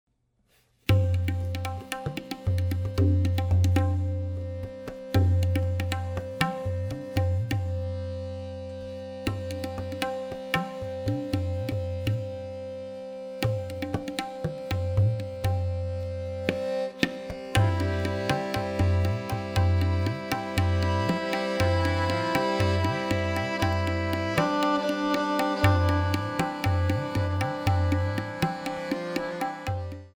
tabla
accordion
violin